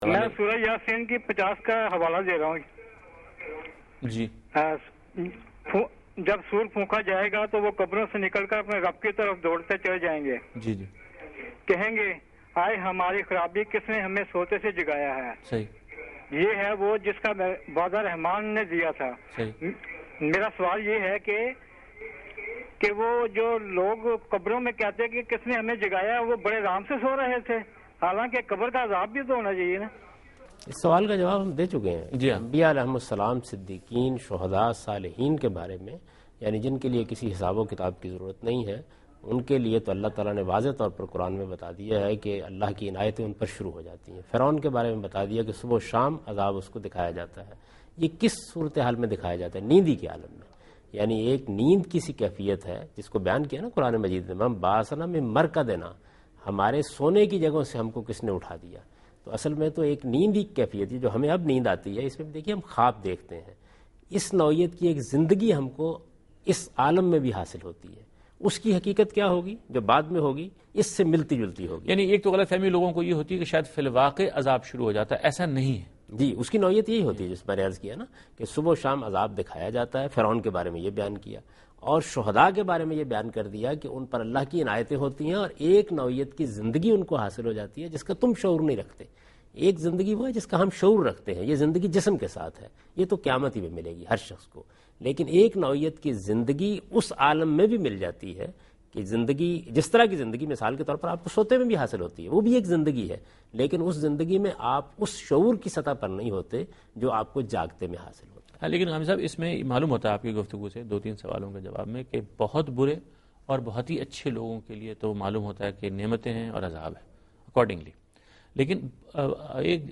Javed Ahmad Ghamidi Answer the Question about Punishment in Grave In Program Deen o Danish
دین ودانش کے اس پروگرام میں جاوید احمد صاحب غامدی عذاب قبر سے متعلق سوال کا جواب دے رہے ہیں